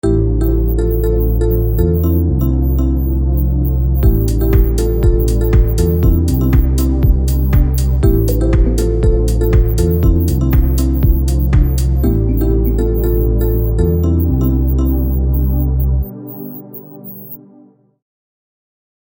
Kategorien Alarm